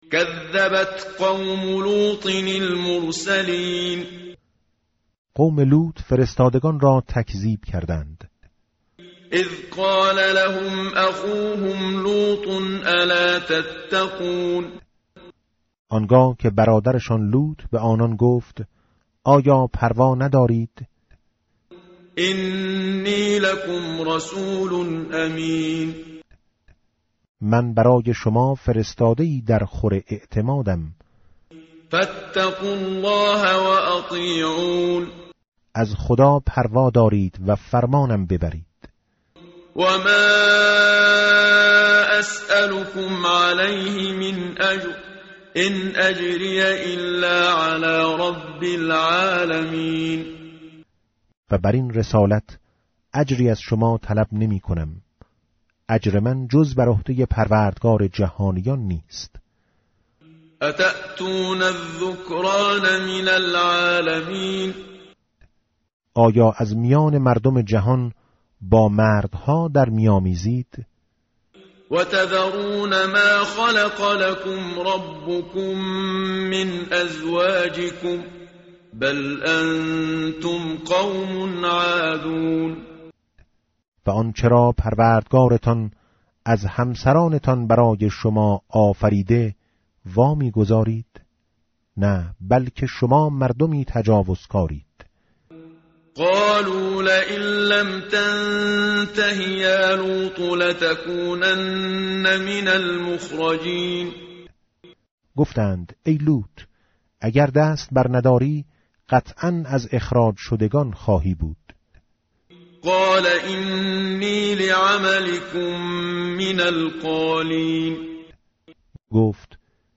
متن قرآن همراه باتلاوت قرآن و ترجمه
tartil_menshavi va tarjome_Page_374.mp3